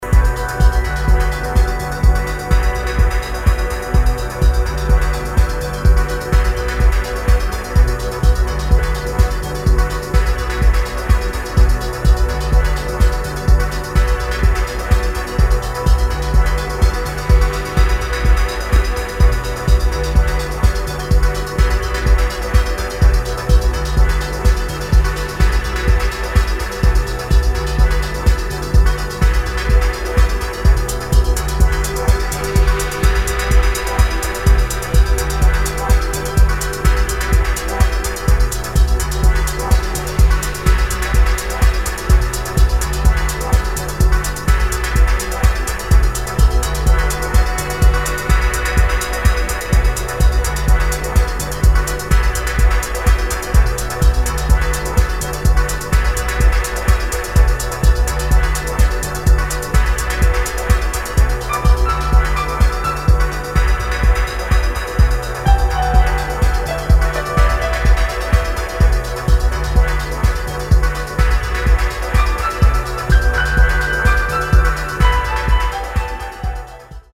[ TECHNO / MINIMAL ]